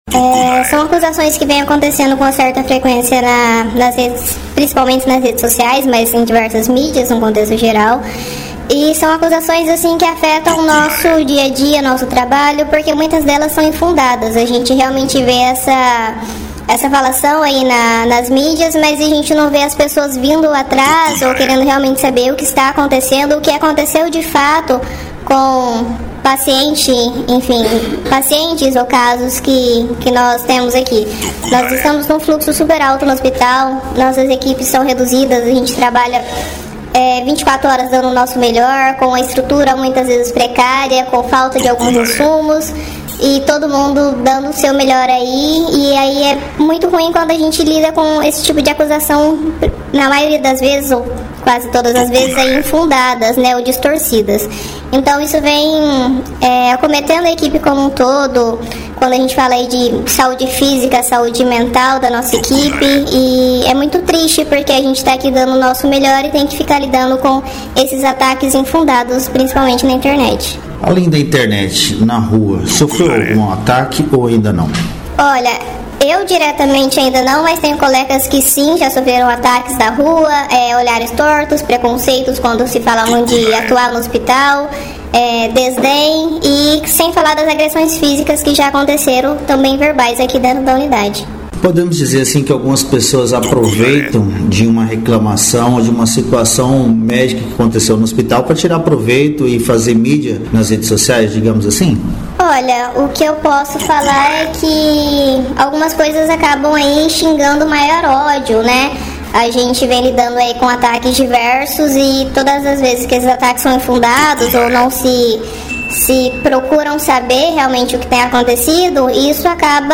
Os áudios com os relatos foram gravados com autorização das servidoras e tiveram suas vozes modificadas para garantir o anonimato, no entanto, o conteúdo completo com a denúncias em áudio modificado estão postadas no final dessa matéria e o conteúdo original, sem alterações, bem como os nomes e identificação ficarão à disposição da justiça, caso seja solicitado.
AUDIOS DAS ENTREVISTAS (COM AS VOZES MODIFICADAS) REALIZADAS NA TARDE DO DIA 28.05.2025 NO HOSP MUNICIPAL DE JUARA NA PRESENÇA DA DIREÇÃO: